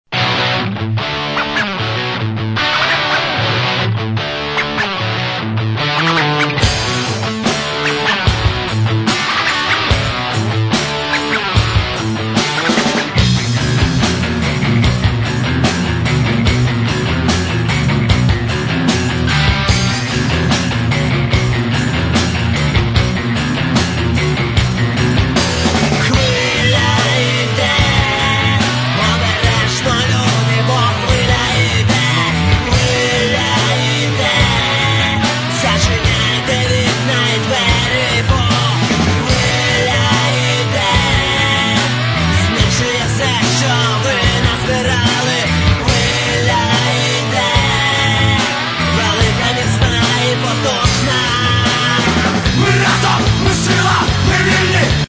Home » CDs» Rock My account  |  Shopping Cart  |  Checkout